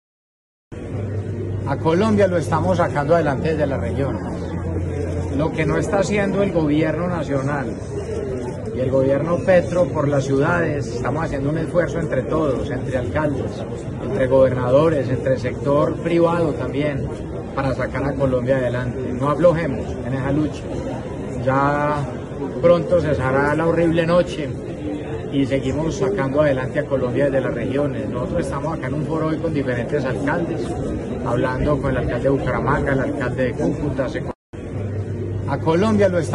En su reciente visita a Bucaramanga en el encuentro de Líderes Regionales el mandatario envió fuerte mensaje al gobierno de Gustavo Petro
Federico Gutiérrez, alcalde de Medellín